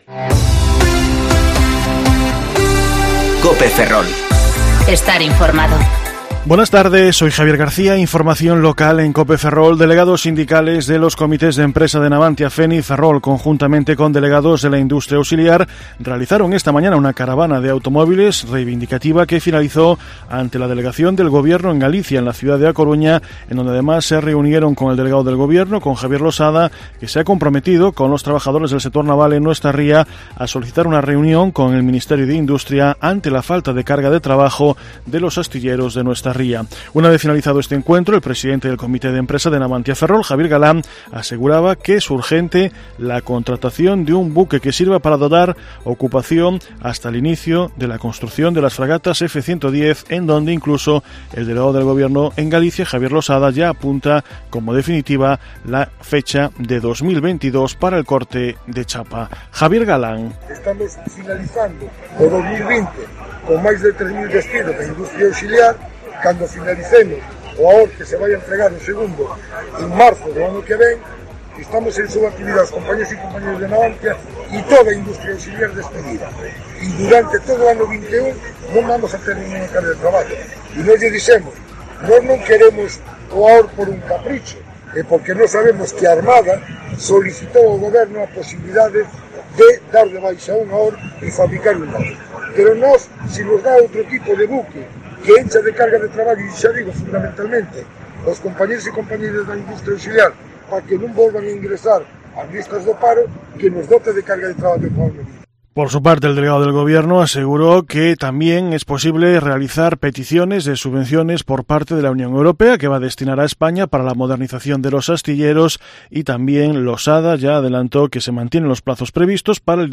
Informativo Mediodía COPE Ferrol 11/9/2020 (De 14,20 a 14,30 horas)